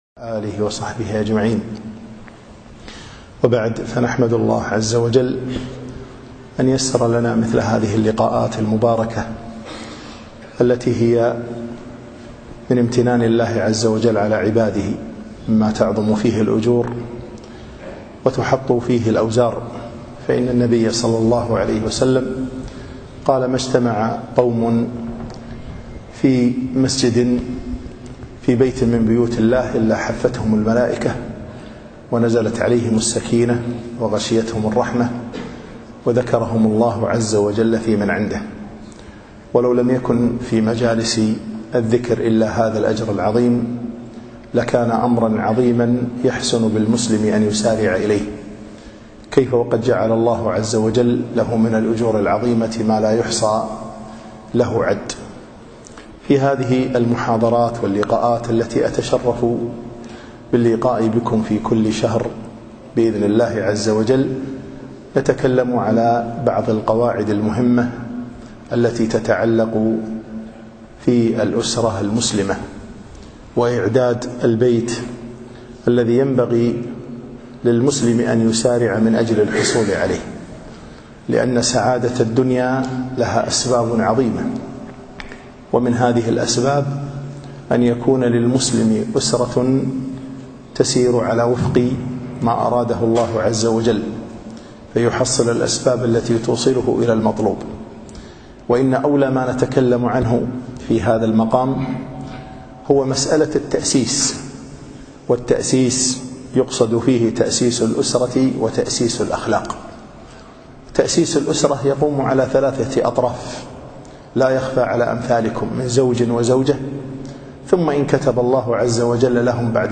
يوم الأربعاء 28 جمادى الأخر 1437 الموافق 6 4 2016 في مسجد زيد بن حارثة سعدالعبدالله
المحاضرة الأولى